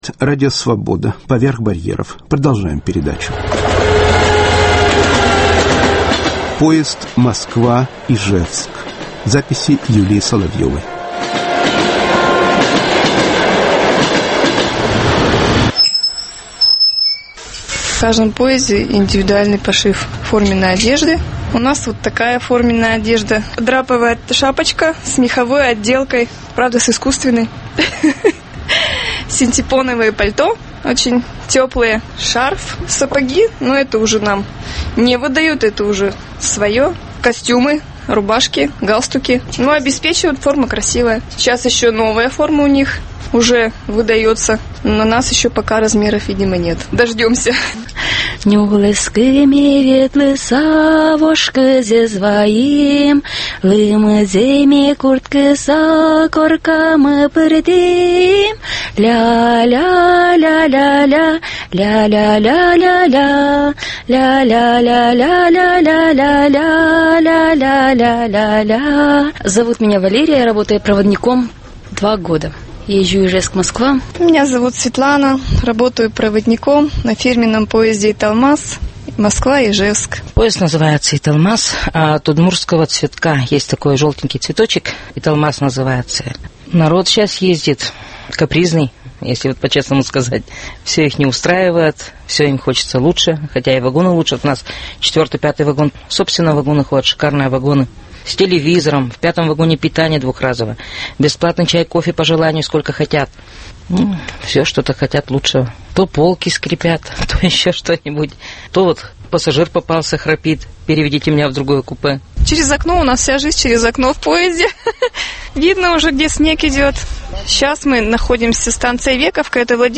"Поезд "Москва-Ижевск" (записи в дороге)